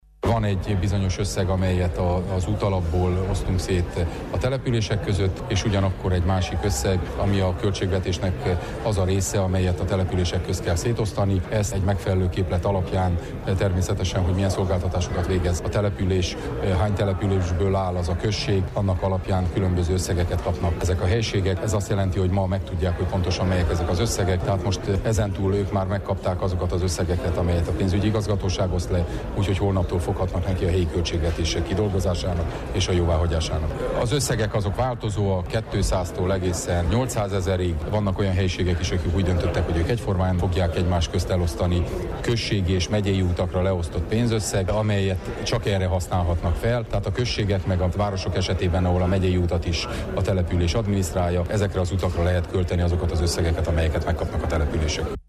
Péter Ferencet a Maros Megyei Tanács elnökét hallják.